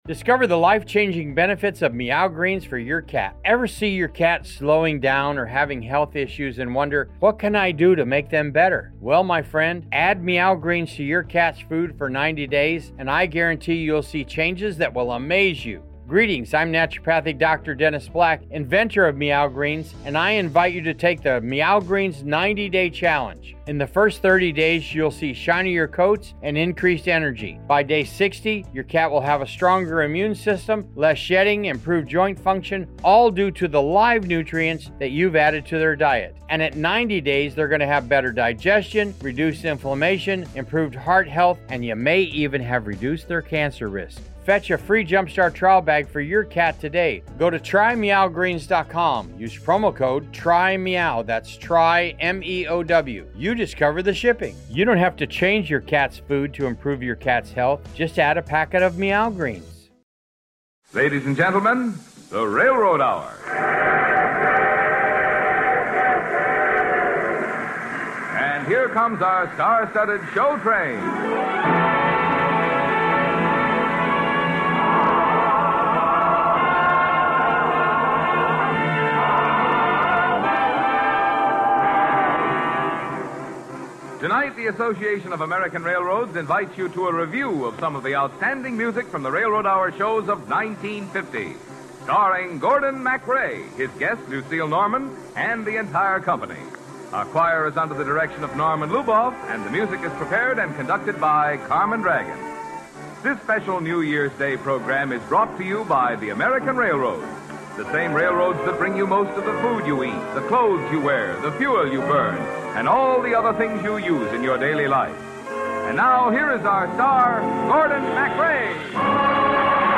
radio series
musical dramas and comedies
hosted each episode and played the leading male roles